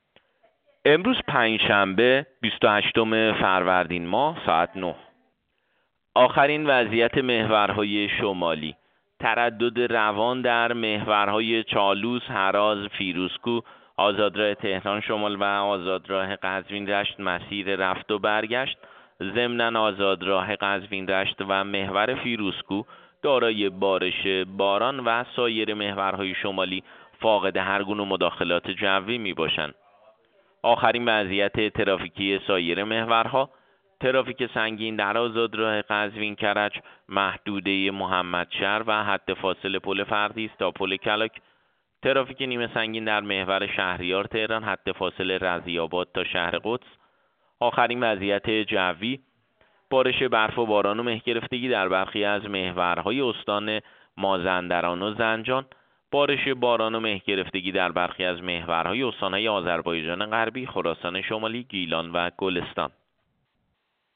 گزارش رادیو اینترنتی از آخرین وضعیت ترافیکی جاده‌ها ساعت ۹ بیست و هشتم فروردین؛